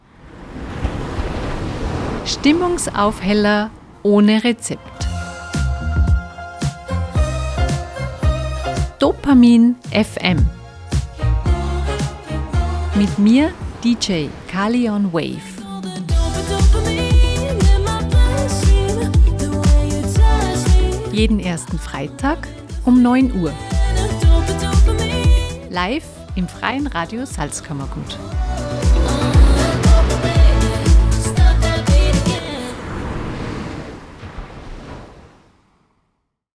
Sendungstrailer
Eine Stunde voller Songs, die zum Mitschwingen, Tagträumen oder Tanzen einladen.